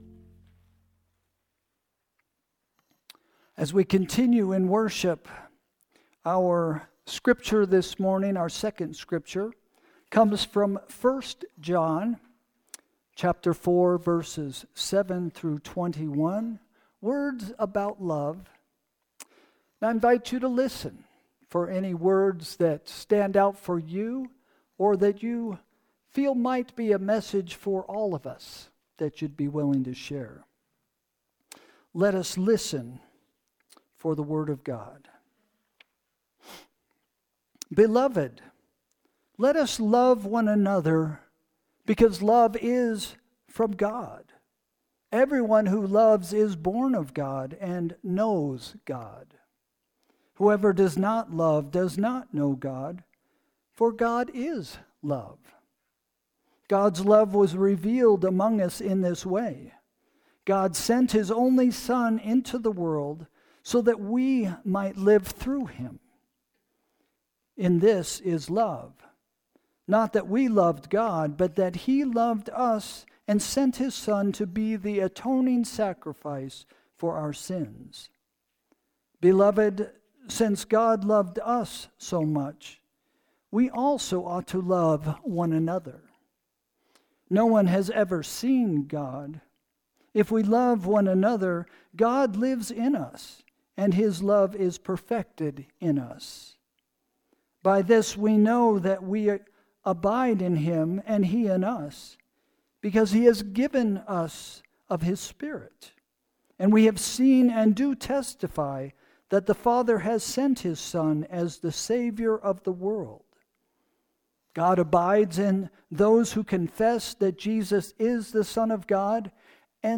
Sermon – December 15, 2024 – First Christian Church